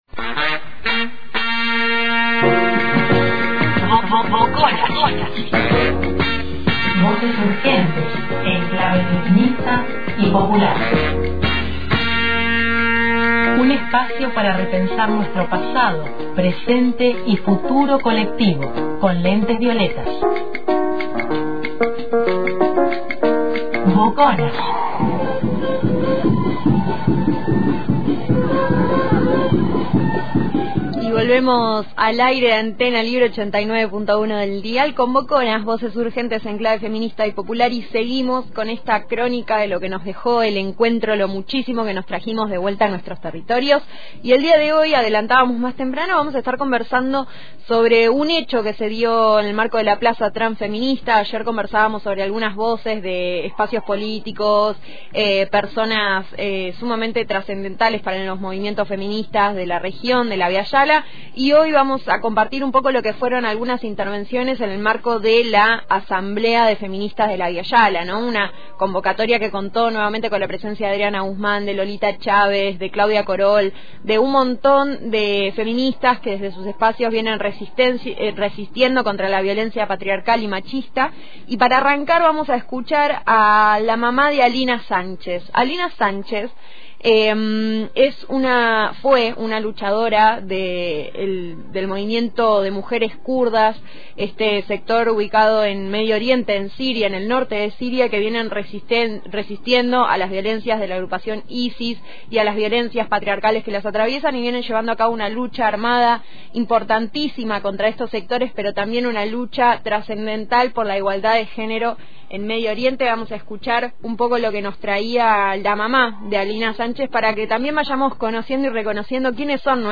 El domingo 9 de octubre se llevó a cabo en la plaza Pringles de San Luis la asamblea de transfeministas del Abya Yala. Esta convocatoria invitó a romper las fronteras que imponen los Estados- Nación, por la construcción de un transfeminismo internacionalista.